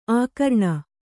♪ ākarṇa